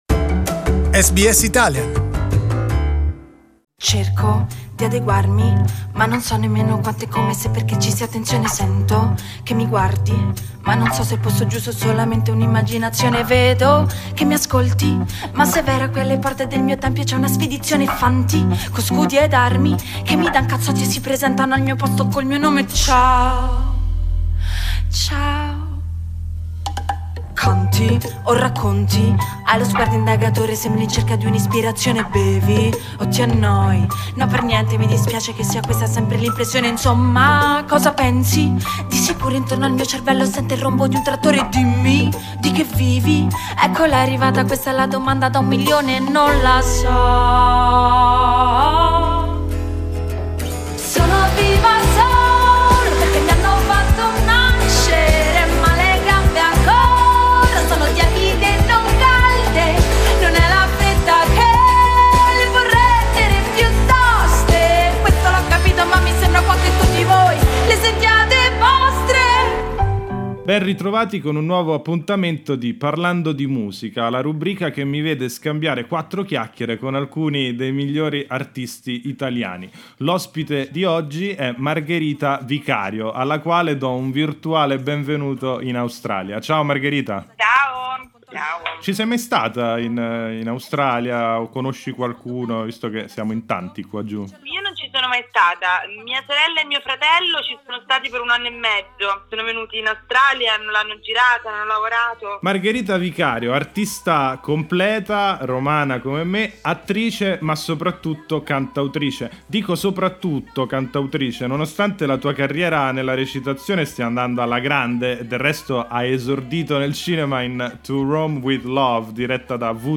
Margherita Vicario si racconta ai microfoni di SBS Italian nella quinta puntata di Parlando di musica.